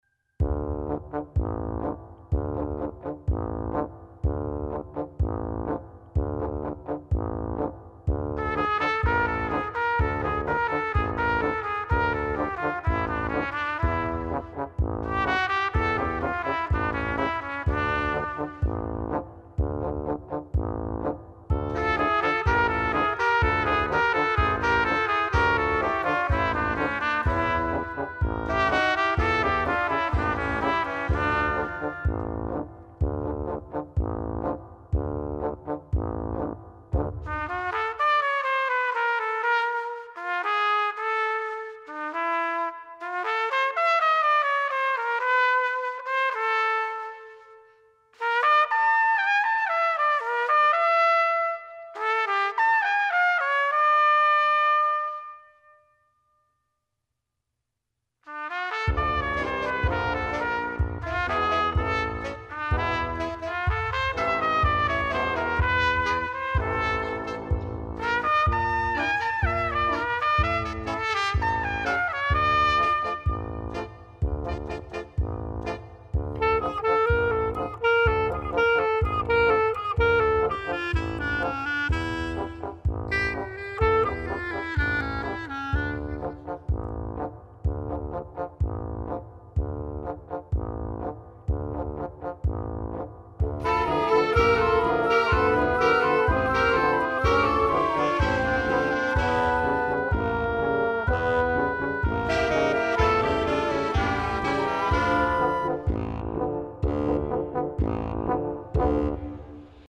Música Original: